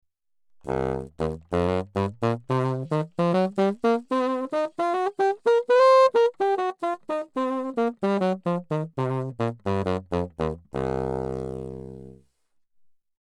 les micros piezo captent le son par vibration à l’intérieur de l’instrument.
Rumberger K1X (celui que j’utilise le plus souvent, micro de bonne qualité, qui retransmet uniformément toutes les harmoniques jusque dans le grave).
test-Rumberger-KX1.mp3